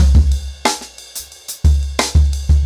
InDaHouse-90BPM.15.wav